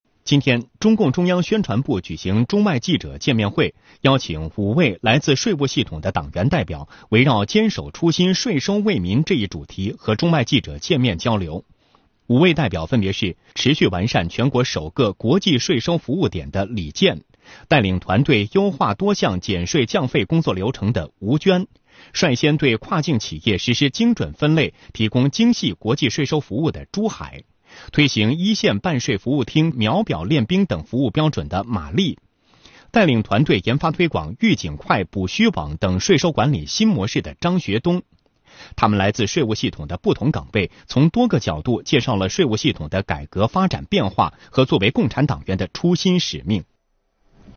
中共中央宣传部举行中外记者见面会
视频来源：央视《新闻联播》
7月15日下午，中宣部“坚守初心 税收为民”中外记者见面会现场，台上税务党员代表深情讲述，台下媒体记者聚精会神，并不时举手追问自己感兴趣的细节。